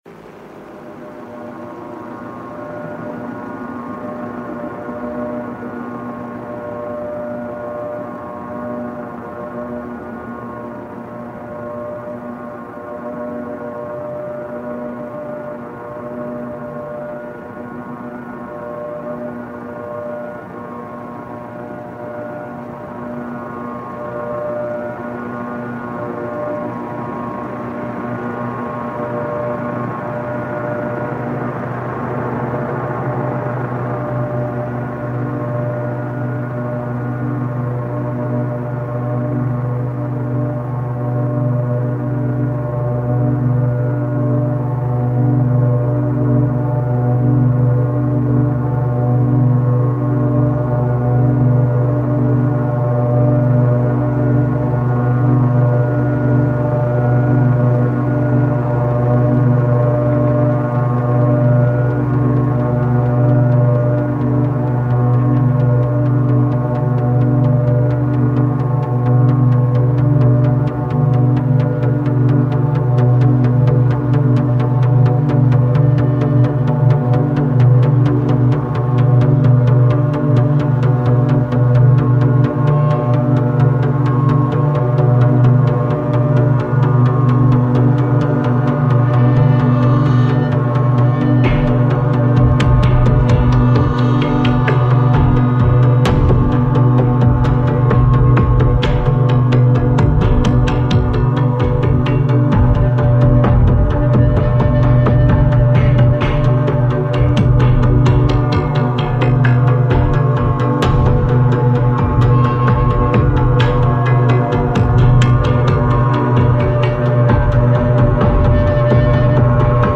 Abandoned hospital sound reimagined